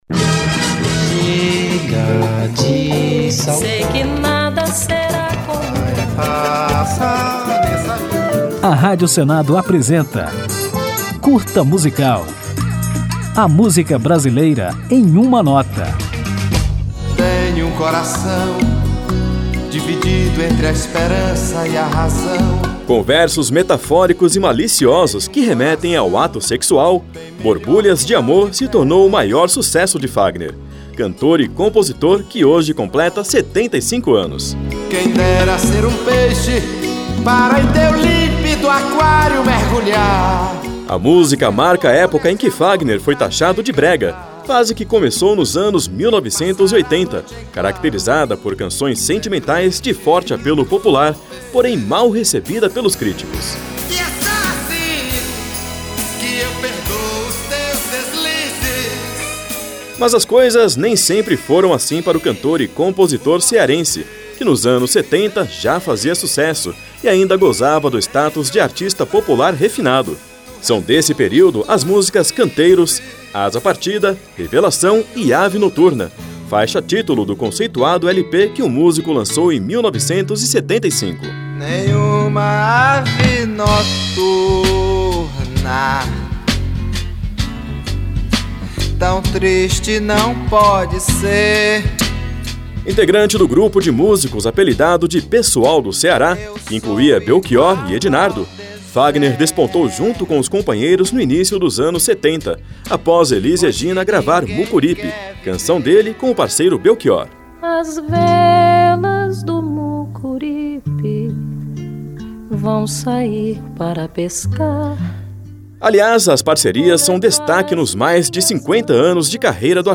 Para comemorar, aperte o play e confira a história deste ícone da MPB, que desde o início dos anos 1970 fez muito sucesso, emplacando músicas como Borbulhas de Amor, Mucuripe, Cavalo Ferro, Deslizes, Ave Noturna, Asa Partida, entre outras. Ao final, ouviremos Canteiros, um dos primeiros sucessos de Fagner, de 1973.